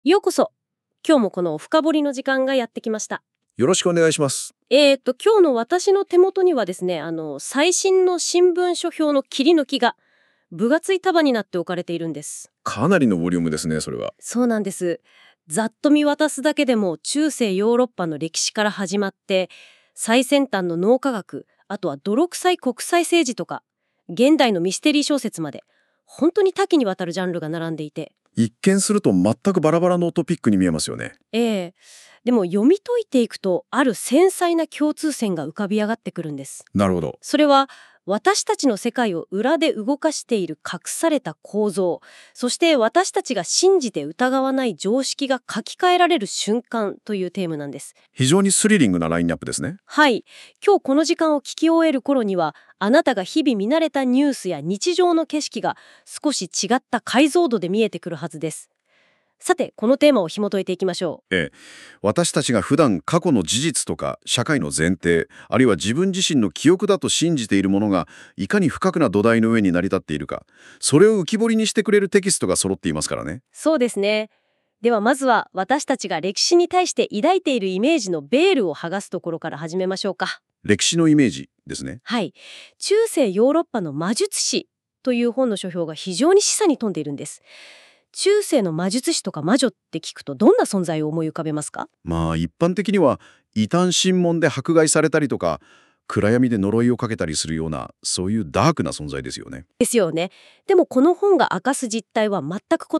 ちなみに、こんなポッドキャスト作ってくれました（新聞の内容に触れる前にカットしてあります）